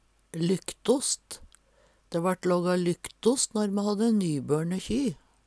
lyktost - Numedalsmål (en-US)